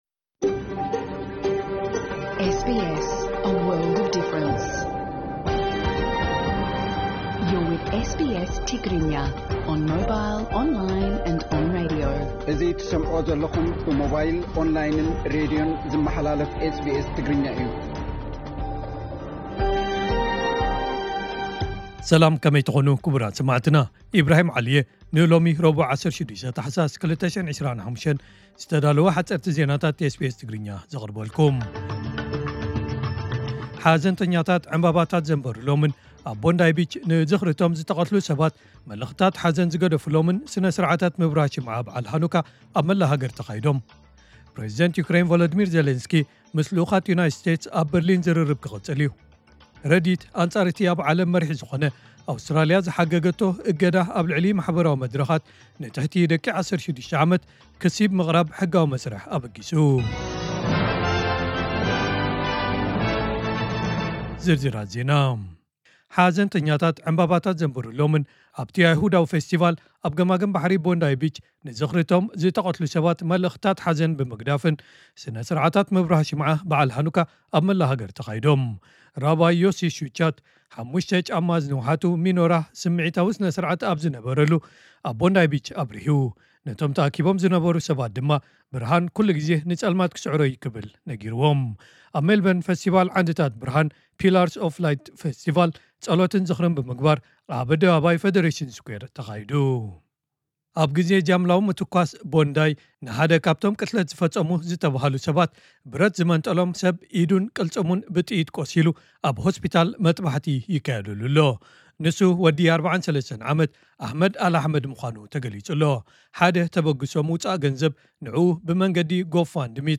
ሓጸርቲ ዜናታት ኤስ ቢ ኤስ ትግርኛ (16 ታሕሳስ 2025)